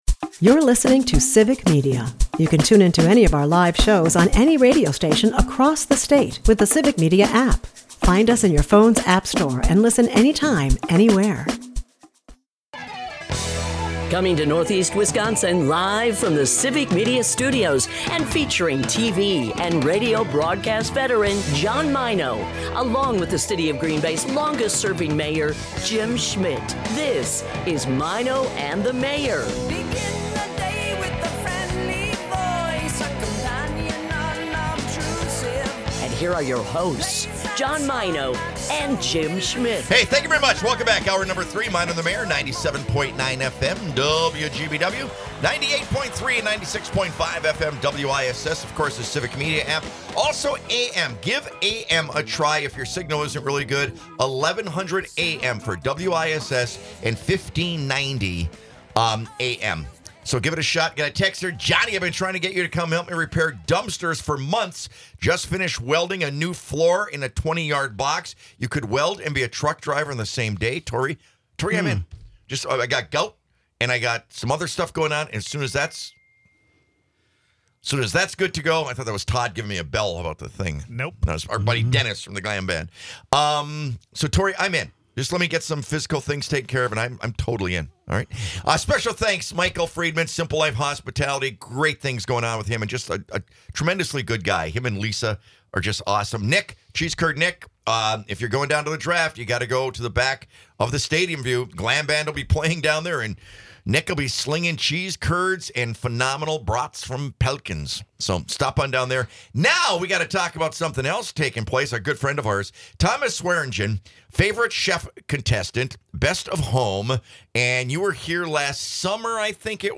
Our first guest is a local chef with a very interesting background.